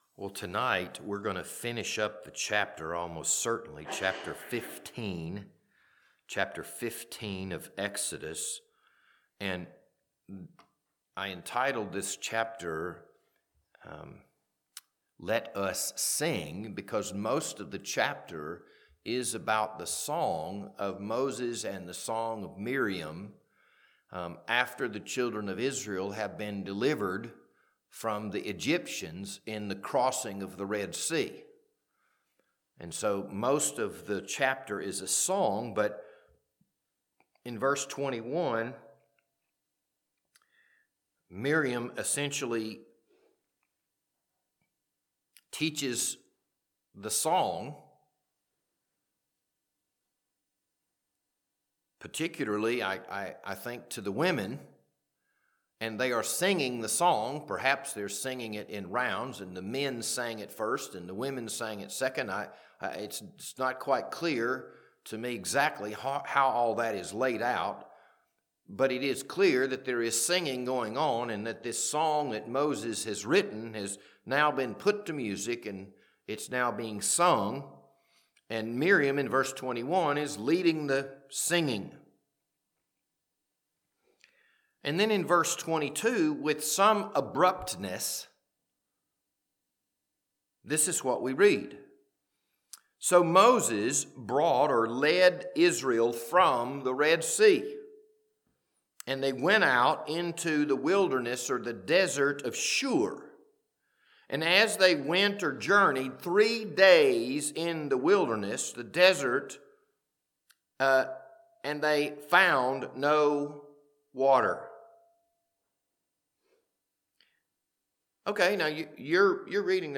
This Wednesday evening Bible study was recorded on February 4th, 2026.